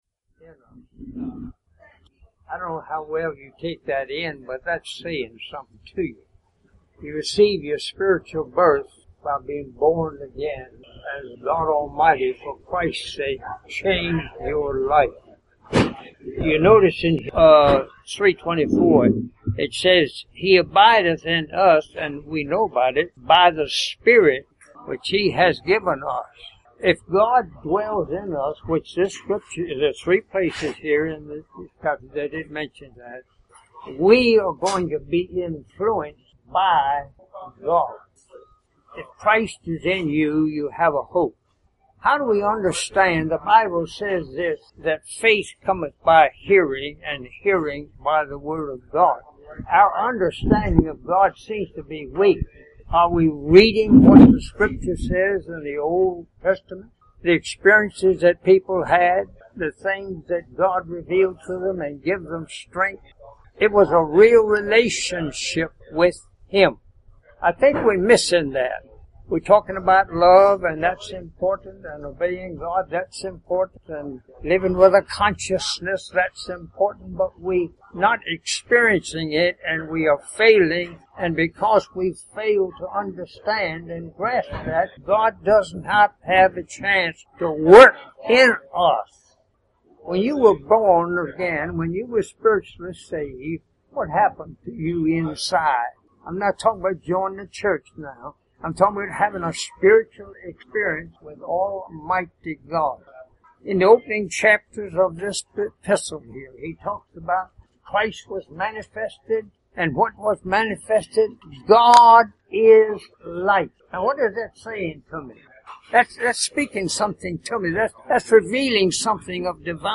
I love his mini-sermons that we sometimes are blessed with on Tuesday mornings, and I happened to catch this one: click this player button: